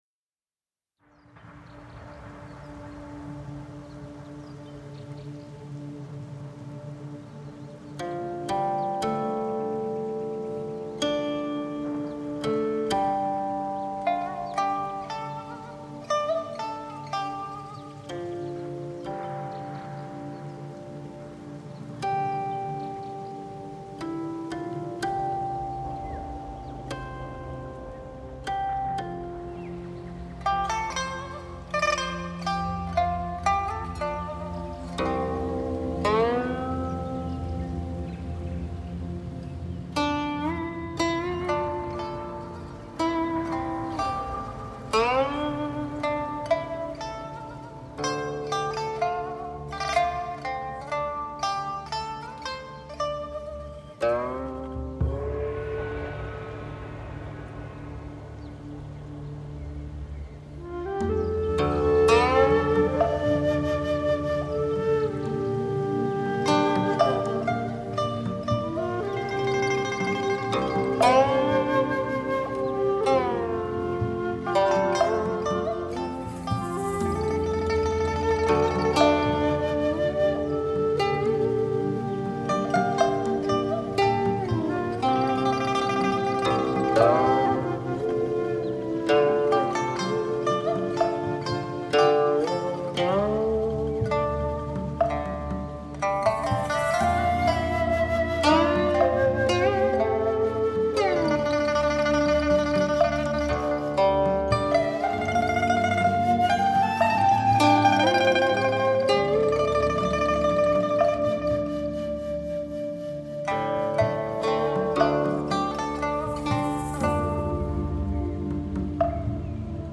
东方禅意音乐系列
琴音相伴，丝丝清香，
古琴演奏
笛箫演奏
笛箫清灵雅性浓